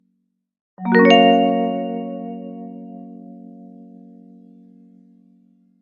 Вы можете слушать онлайн и скачивать различные вопросительные интонации, загадочные мелодии и звуковые эффекты, создающие атмосферу тайны.